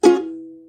Звук мелодии укулеле
Мягкий звук укулеле превращает любой проект в настоящее чудо!
Укулеле бренчание
ukulele_brenchanie_dui.mp3